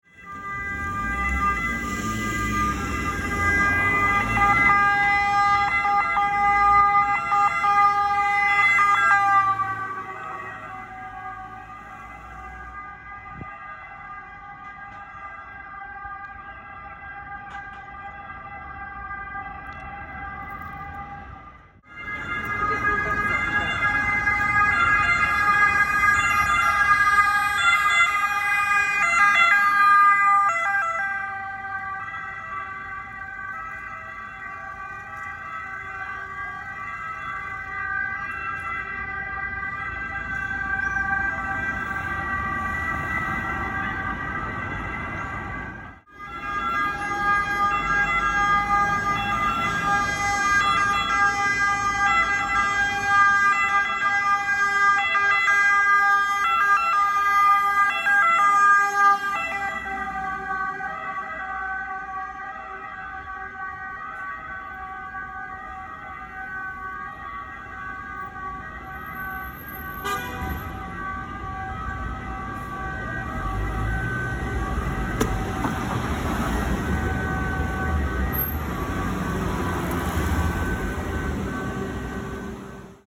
Сборник со звуками кареты скорой помощи для монтажа видео и других проектов.
12. Характерный звук сирены машины скорой помощи, как она звучит в Италии
sirena-skoroi-italy.mp3